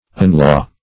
Search Result for " unlaugh" : The Collaborative International Dictionary of English v.0.48: Unlaugh \Un*laugh"\, v. t. [1st un- + laugh.] To recall, as former laughter.